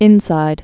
(ĭnsīd) also Inland Passage